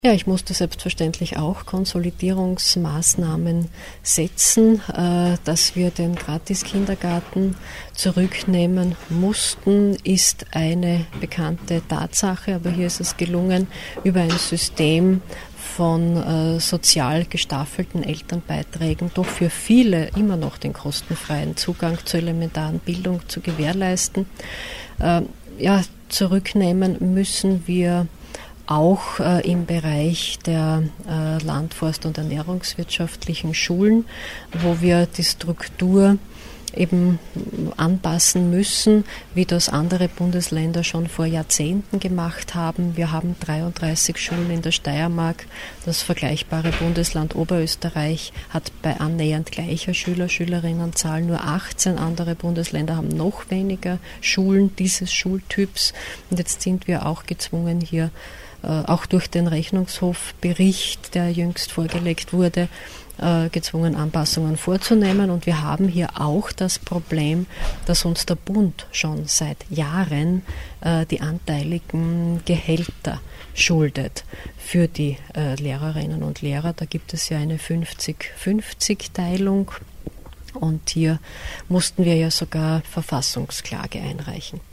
O-Töne Landesrätin Elisabeth Grossmann: